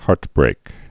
(härtbrāk)